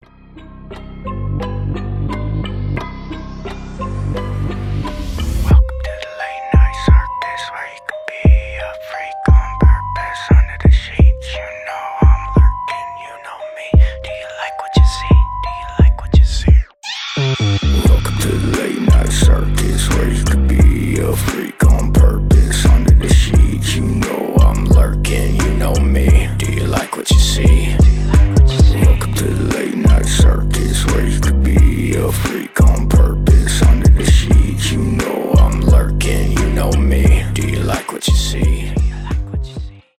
рэп
пугающие , шепот , страшные